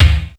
80s Digital Kick 02.wav